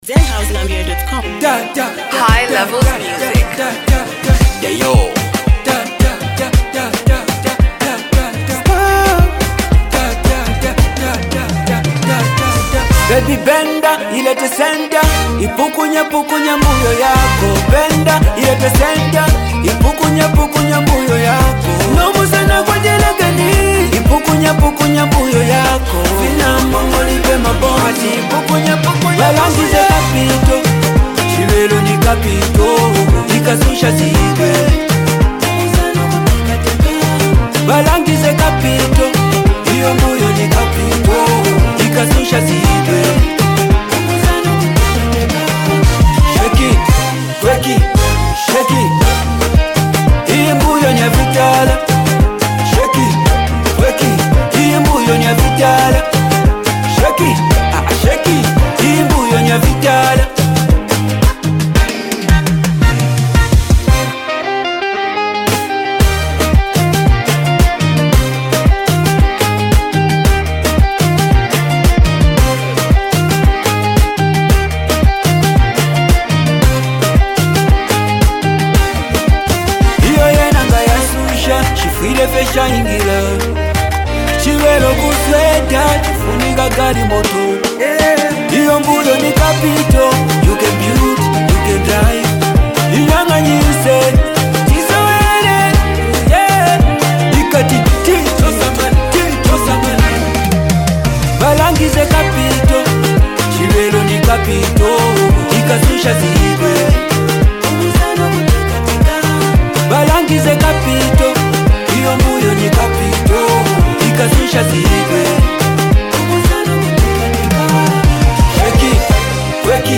motivational anthem